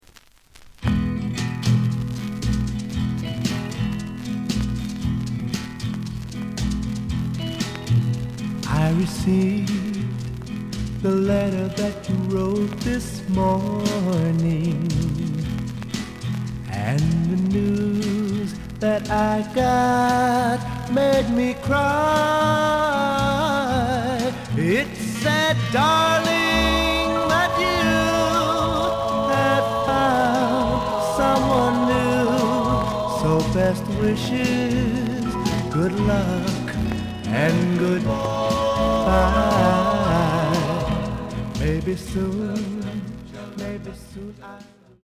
The listen sample is recorded from the actual item.
●Format: 7 inch
●Genre: Rhythm And Blues / Rock 'n' Roll